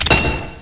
leverthrow.wav